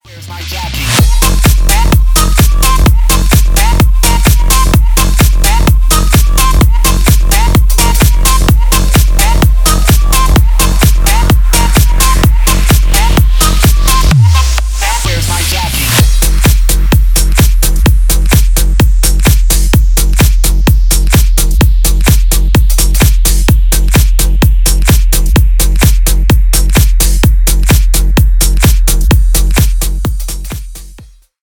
Ремикс
клубные # ритмичные # без слов